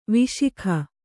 ♪ viśikha